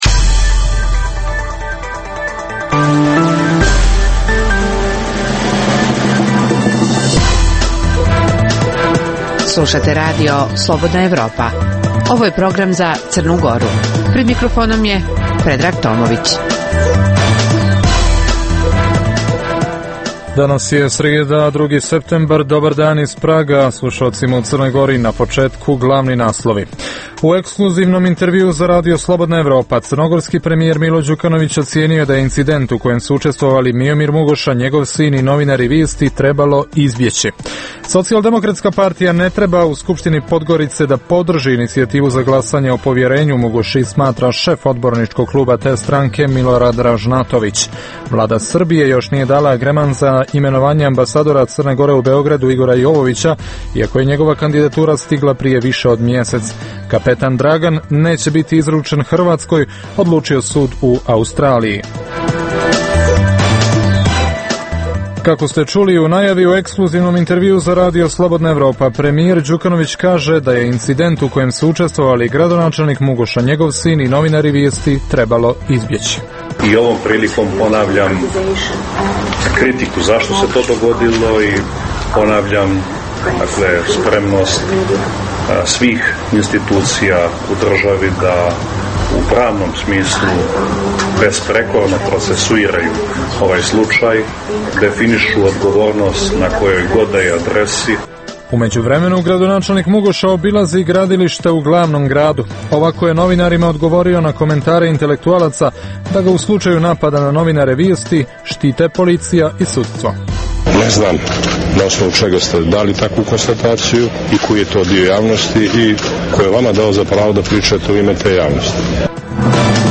- U emisiji za Crnu Goru prenosimo najvažnije akcente iz ekskluzivnog intervjua RSE crnogorskog premijera Mila Đukanovića koji je najavio brojne višemilionske investicije i reagovao na kritike da Crna Gora nema nezavisne institucije.